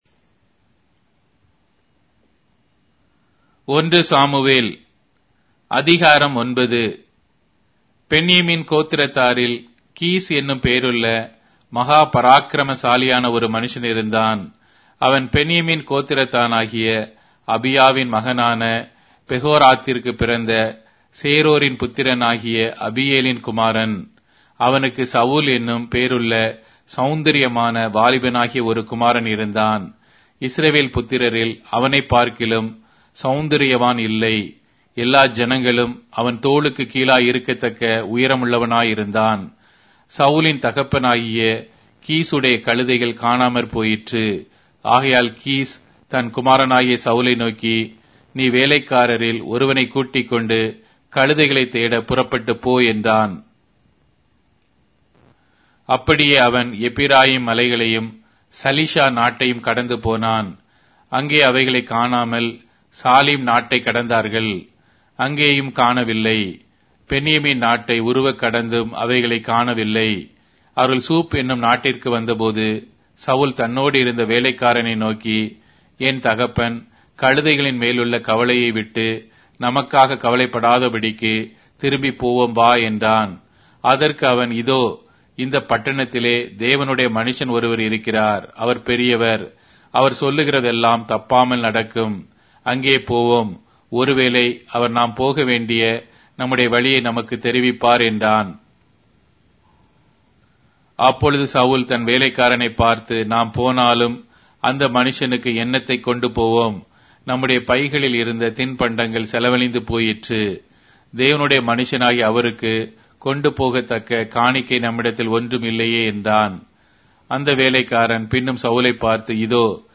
Tamil Audio Bible - 1-Samuel 8 in Ncv bible version